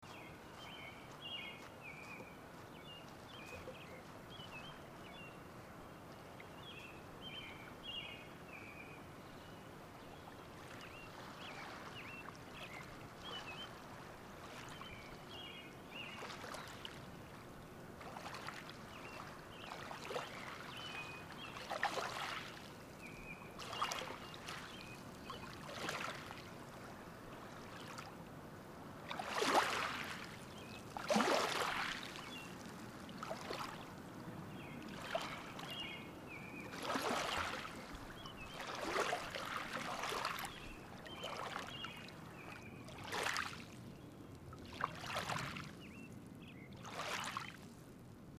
Listen to an excerpt from the soundscape.